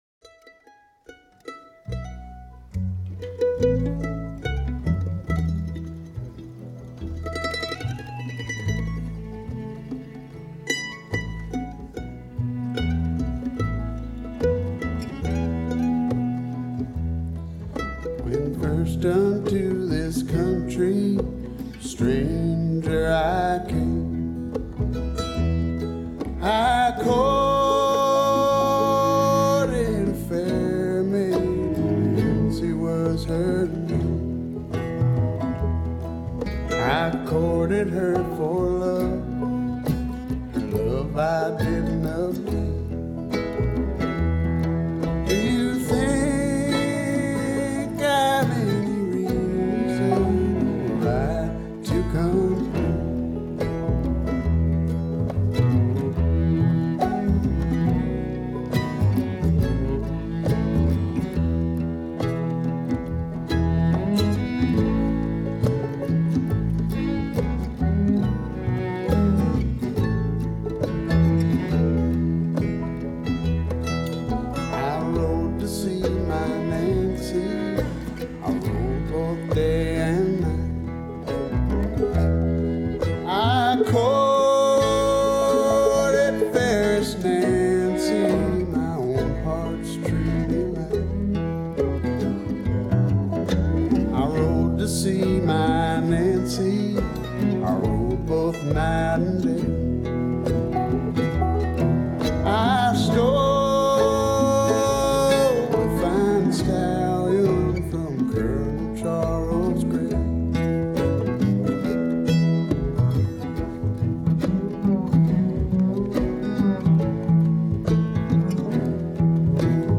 Bass And Vocals
Mandolin And Vocals
Flat Top Guitar And Vocals
Banjo
Cello
Percussion And Vocals
Resonator Guitar
Support Live Music!